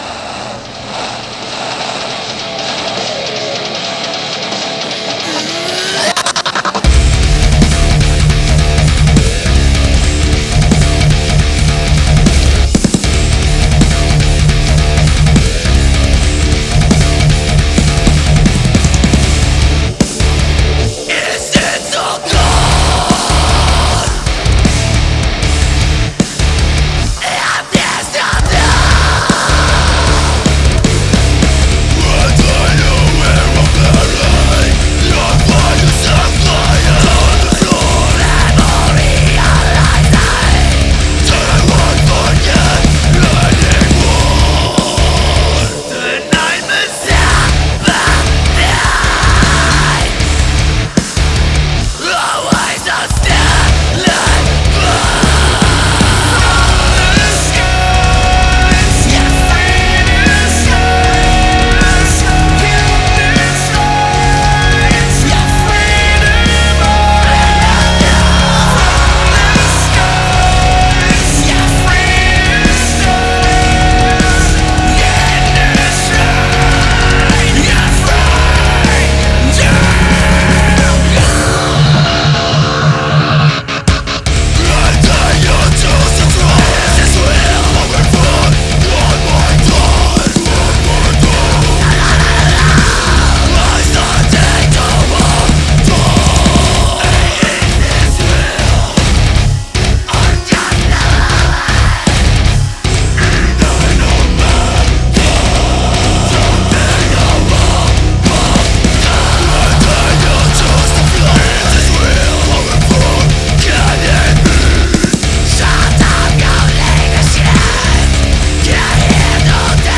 Playing “Experimentalcore” from Turin, Italy since 2015.
Guitar
Drums